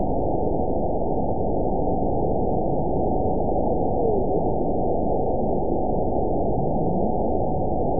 event 922528 date 01/25/25 time 22:58:38 GMT (4 months, 3 weeks ago) score 9.06 location TSS-AB01 detected by nrw target species NRW annotations +NRW Spectrogram: Frequency (kHz) vs. Time (s) audio not available .wav